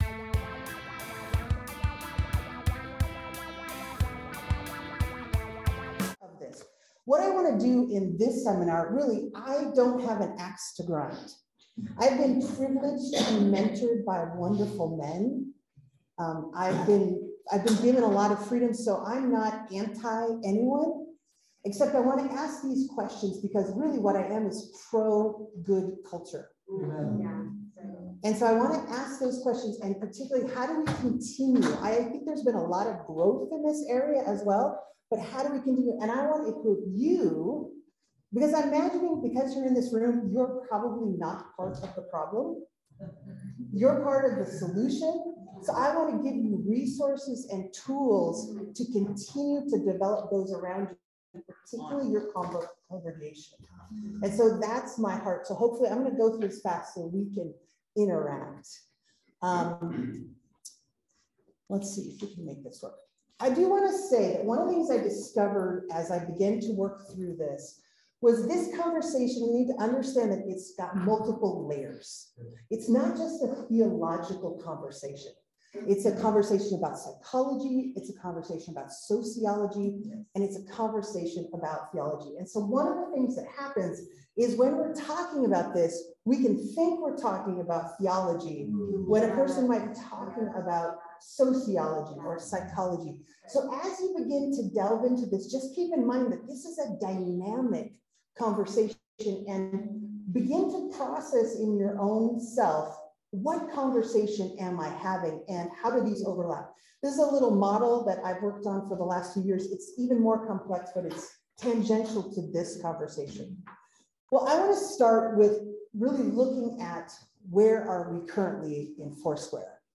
Workshop recording from the 2022 Foursquare Leadership and Education Forum.